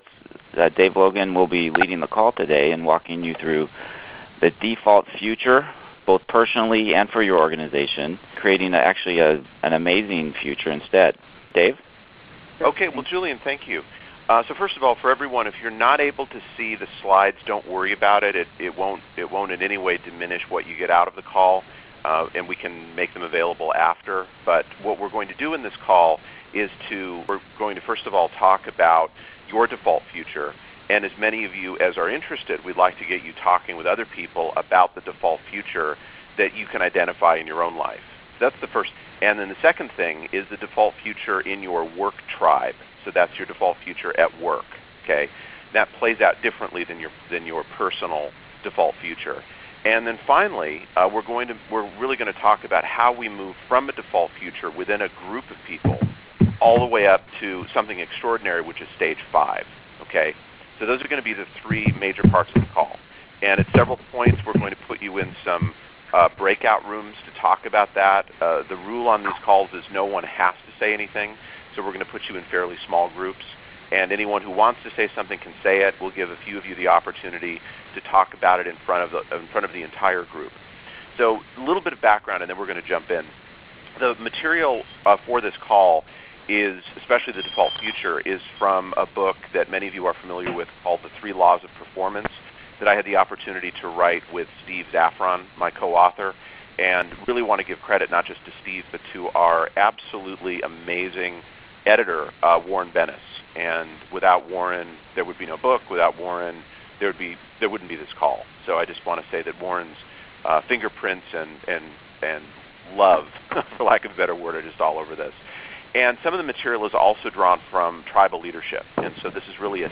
Recording of Default Future to Stage Five Tribes call